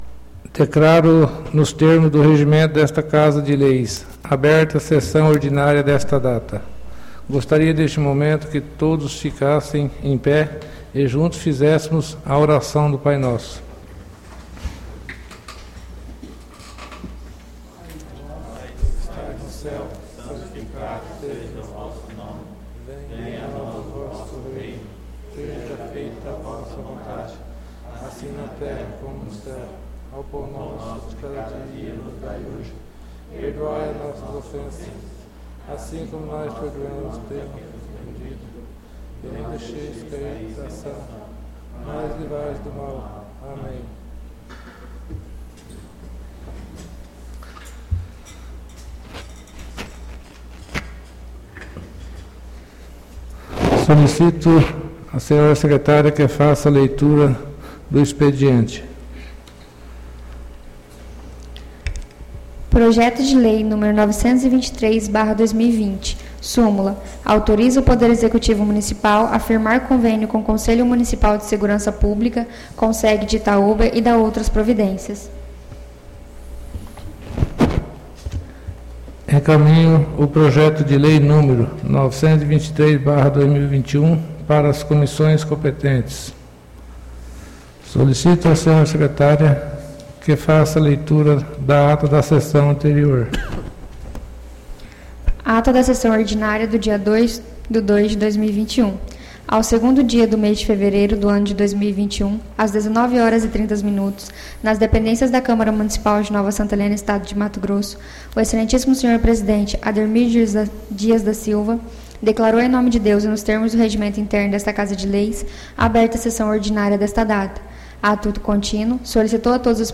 ÁUDIO SESSÃO 09-02-21 — CÂMARA MUNICIPAL DE NOVA SANTA HELENA - MT